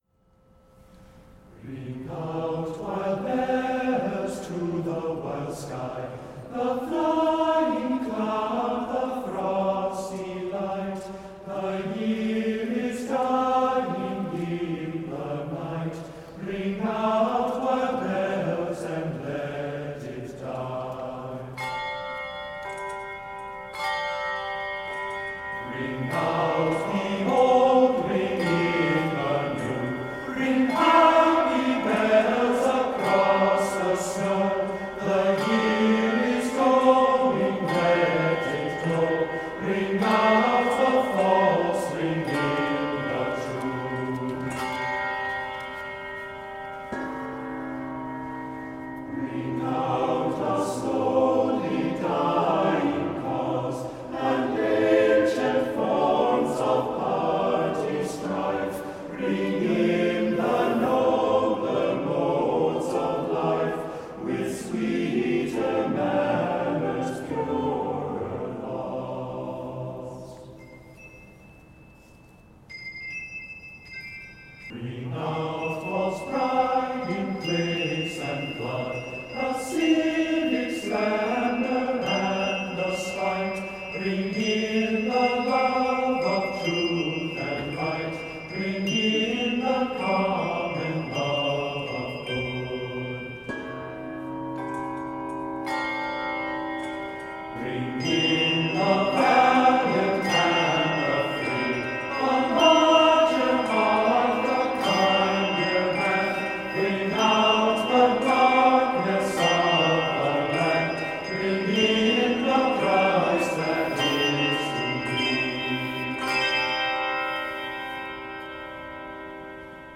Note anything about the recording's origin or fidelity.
recorded on New Year’s Day 2017